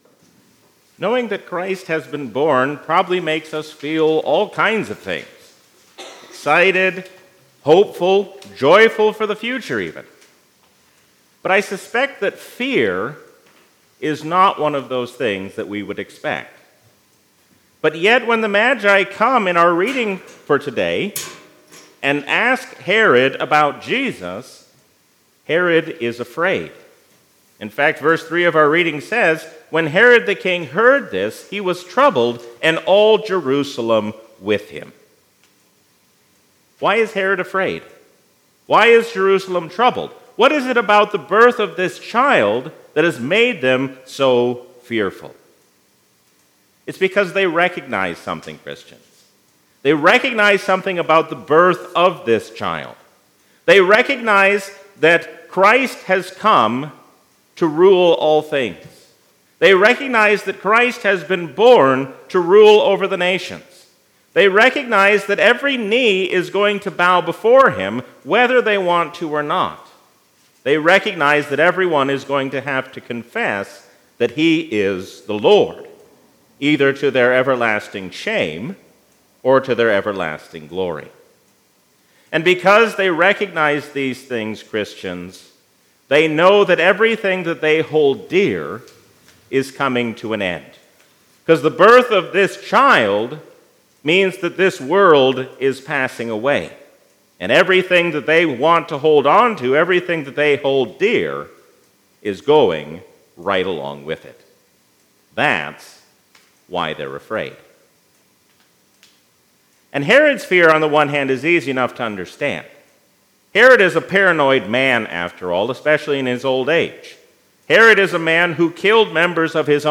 A sermon from the season "Christmas 2021." After today our lives will never be the same, because Jesus has been born.